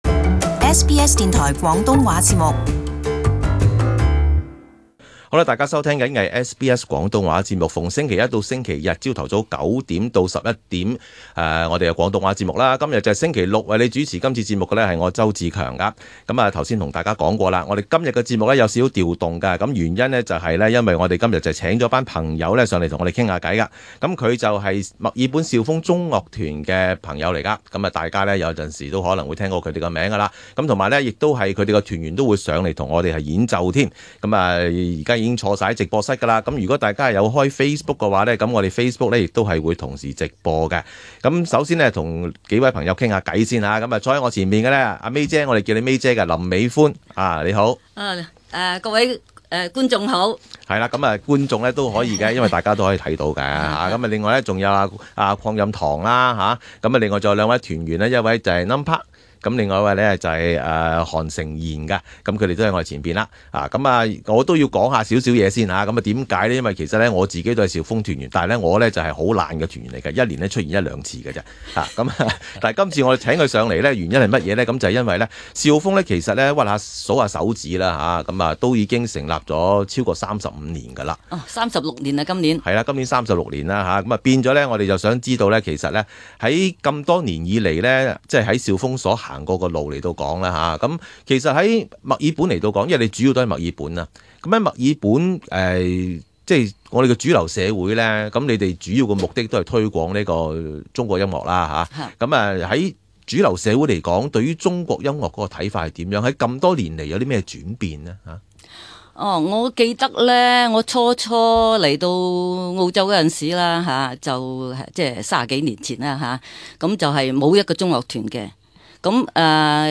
訪問墨爾本肇風中樂團